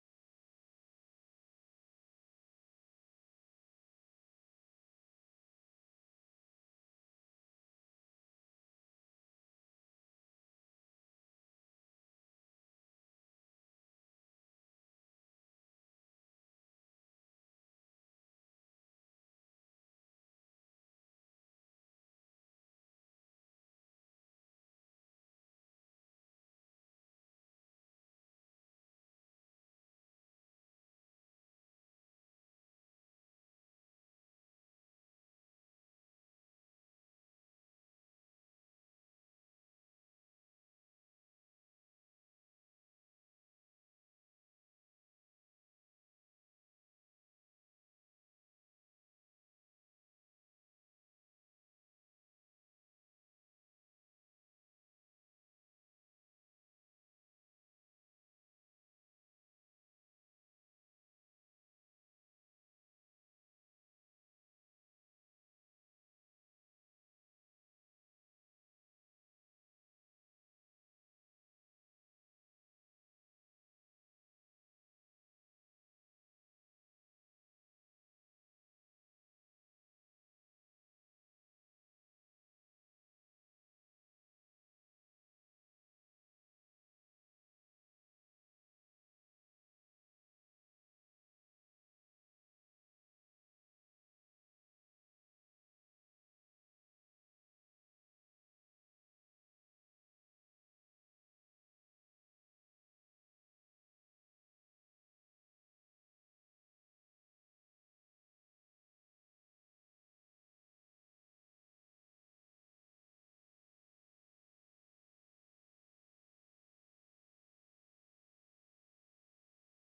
Unfortunately we had an audio issues. The first few minutes we lost sound. It picks up when it is restored.